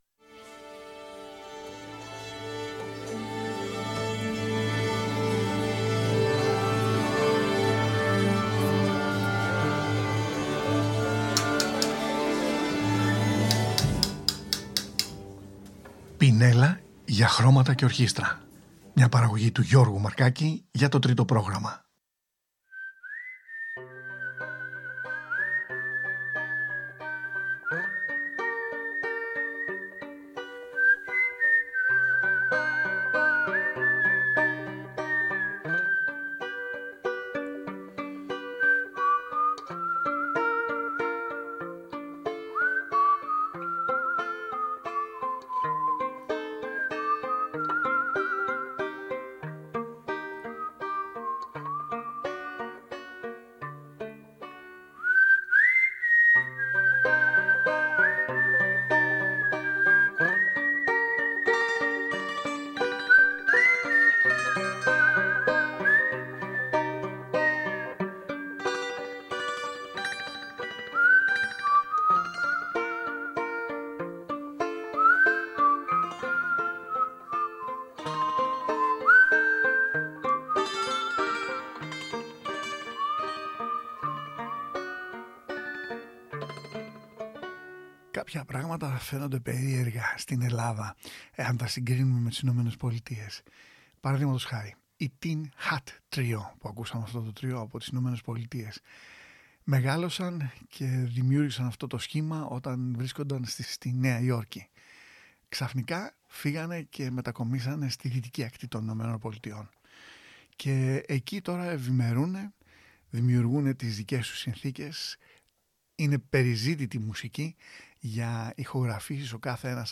Άρπα κλασική και Kora Δυτικής Αφρικής
Παρουσίαση της Δυτικο-αφρικανικής άρπας σήμερα που ονομάζεται Kora στην σημερινή εκπομπή. Παρουσιάζονται έργα καλλιτεχνών που παίζουν είτε σαν σολίστ ή είτε μέσω της σύμπραξης τους με άλλους μουσικούς.